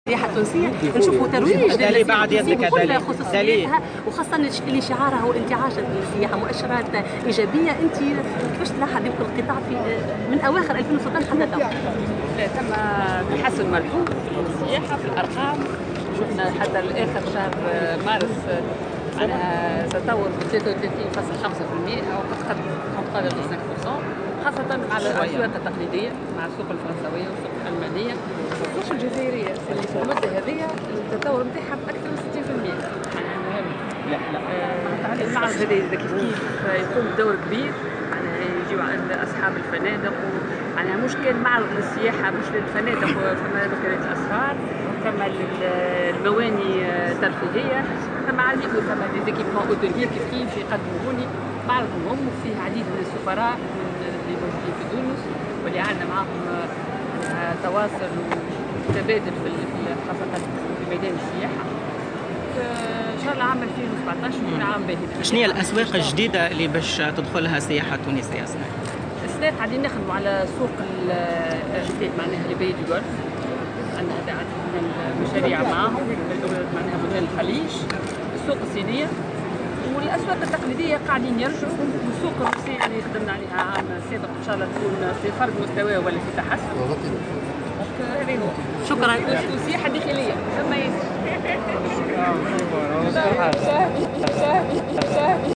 Dans une déclaration à la presse lors de l’inauguration de la 23ème édition du Marché international du tourisme (MIT) qui se tient au parc des expositions du Kram, la ministre a expliqué ces résultats par la progression des marchés traditionnels essentiellement francais, allemand et algerien (60%).